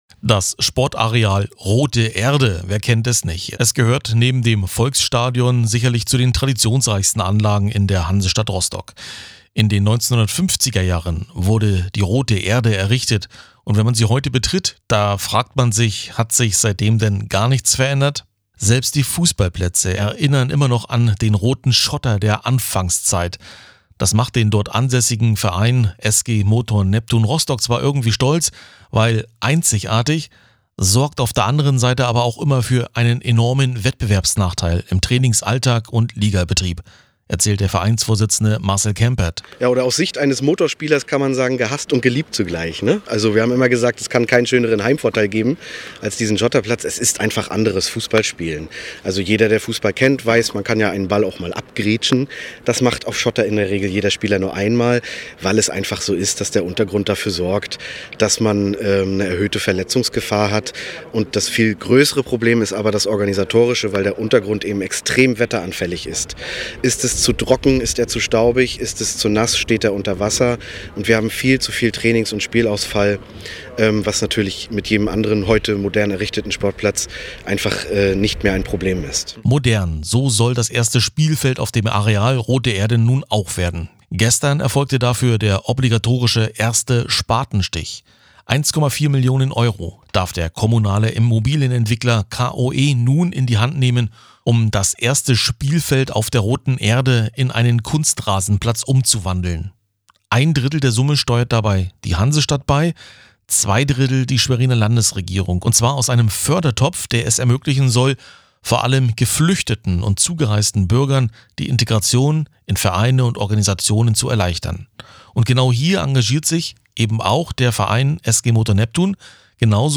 Beitrag zum obligatorischen ersten Spatenstich